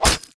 SFX_ATTACK_SWORD_001
designed fight game impact medieval sword video-game violence sound effect free sound royalty free Gaming